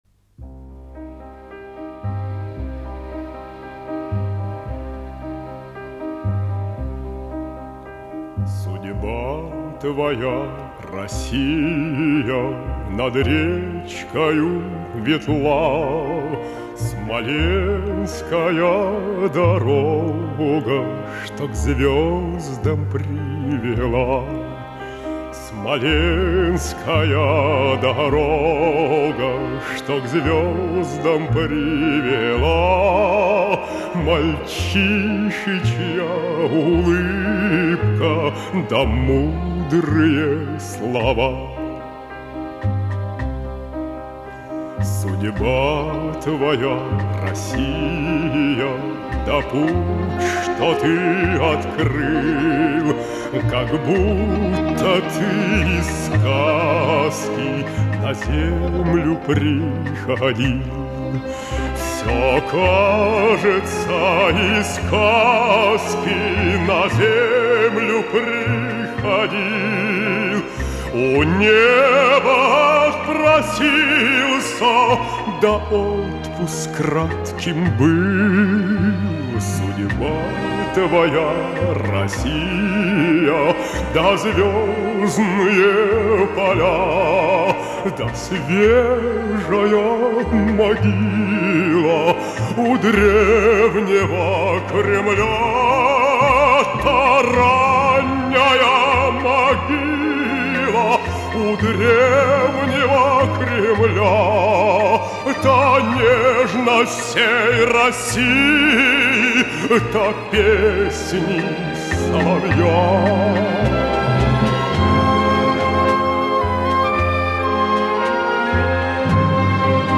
Эта печальная песня написана после гибели Гагарина.